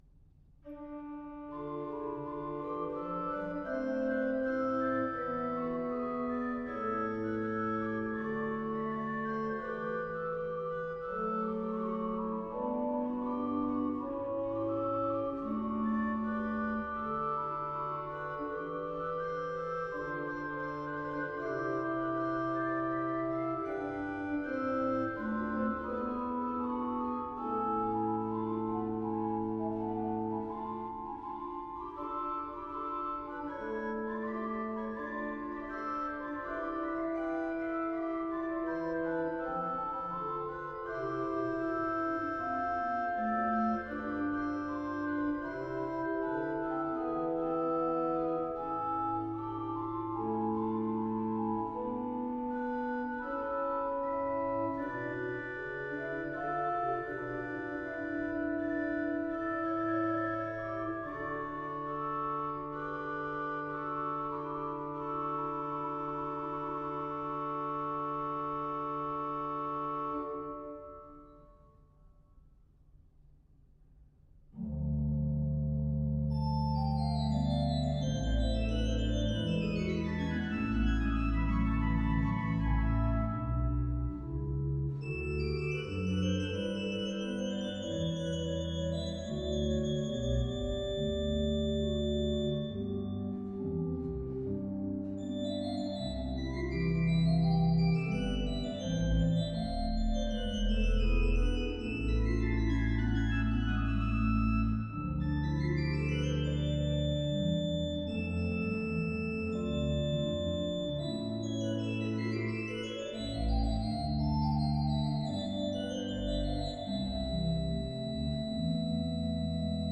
Organ  (View more Intermediate Organ Music)
Classical (View more Classical Organ Music)